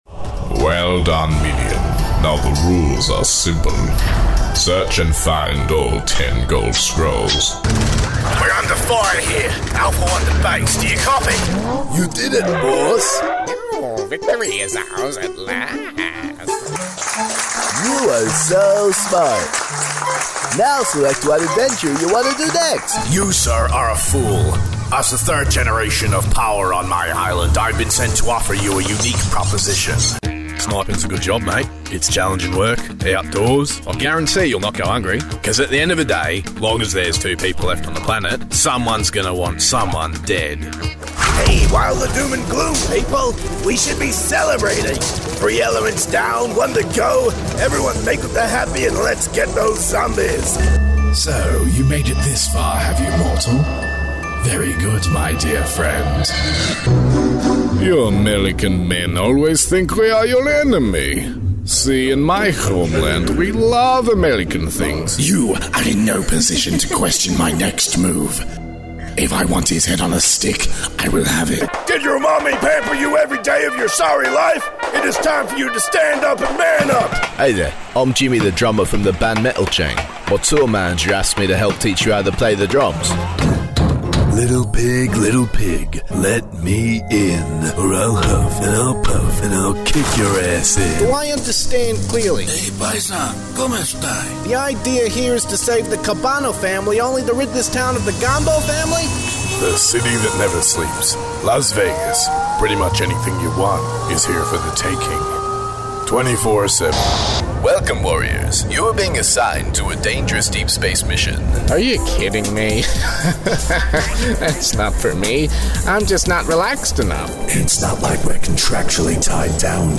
Has Own Studio
G’day, I’m an Australian character voice talent with a broad range of character voice overs and vocal capabilities!
SHOWREEL